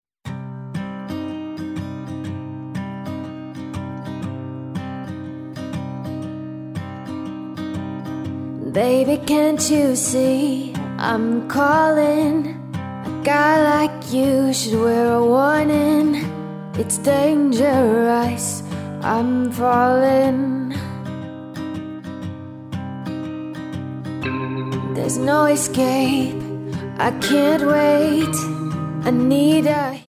--> MP3 Demo abspielen...
Tonart:Cm Multifile (kein Sofortdownload.
Die besten Playbacks Instrumentals und Karaoke Versionen .